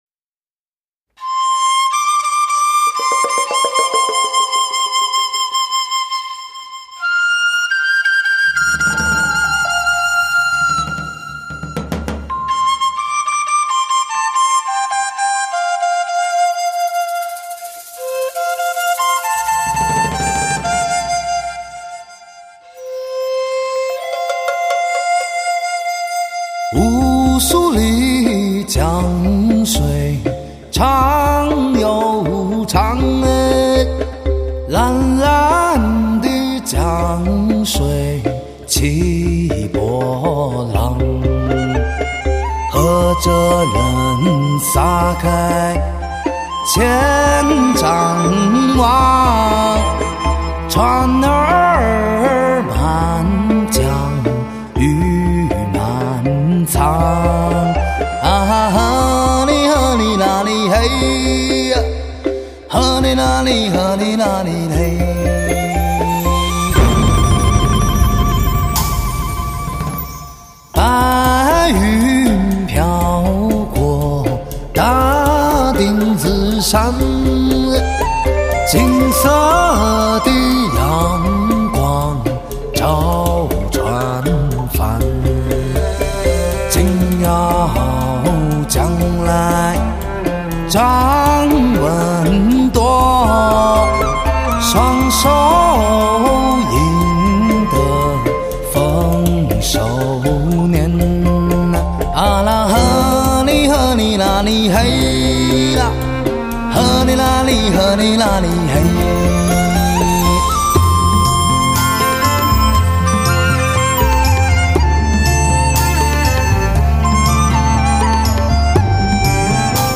唱片类型：汽车音乐
Live高临场感CD！